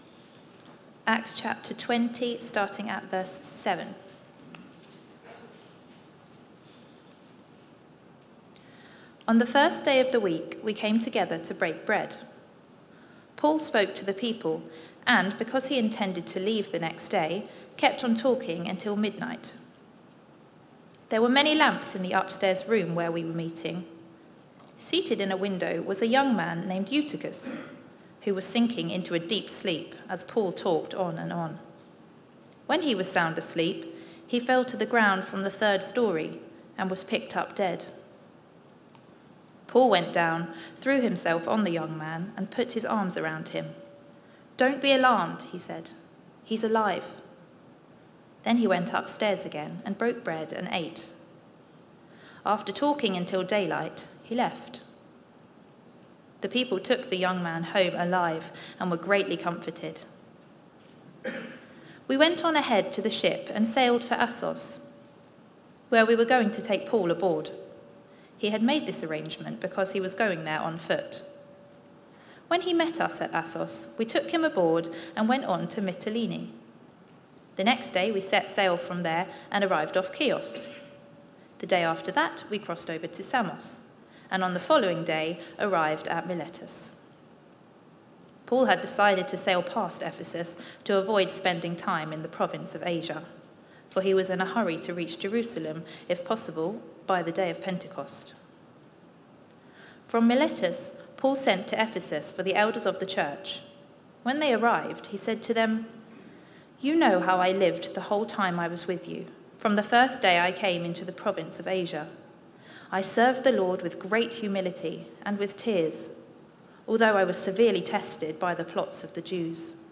Media for Sunday Service on Sun 20th Oct 2024 10:00
Passage: Acts 20 Series: Book of Acts Theme: Sermon